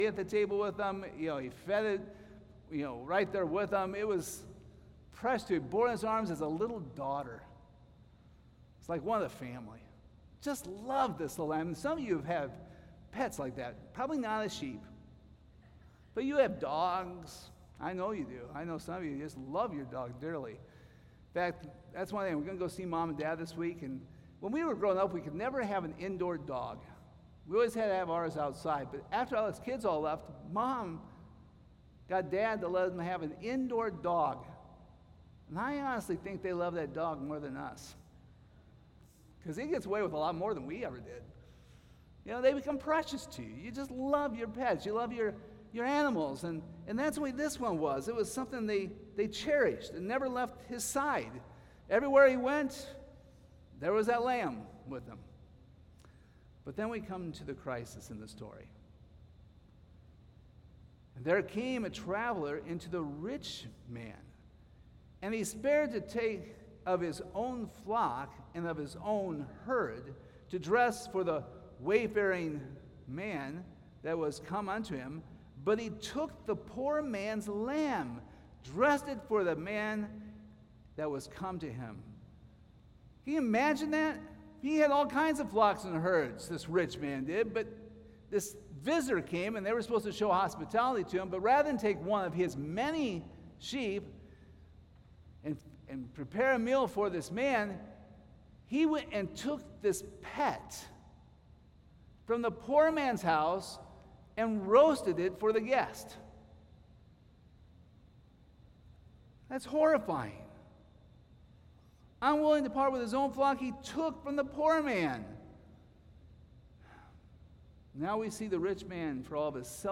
We apologize for the abrupt start of this message, we started the recording a little late.